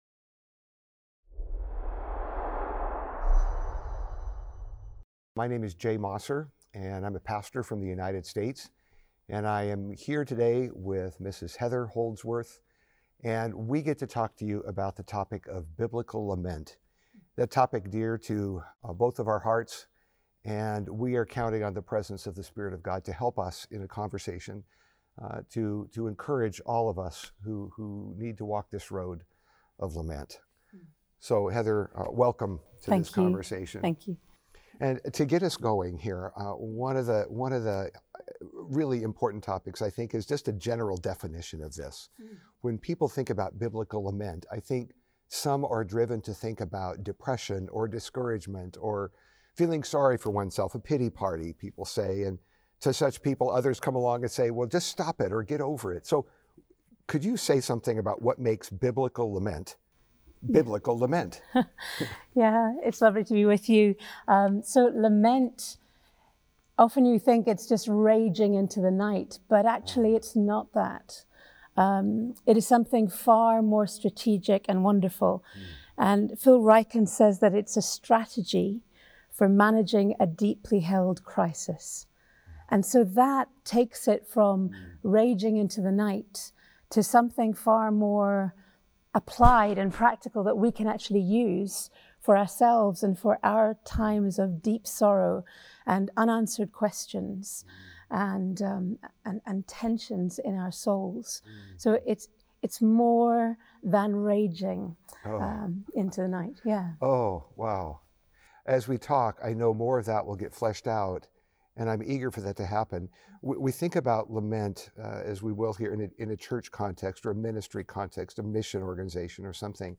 But how do we voice our aches, and how do we help those we lead to not walk away from God when trouble comes? This interview gives invaluable practical tools to grow our trust in God in the midst of pain.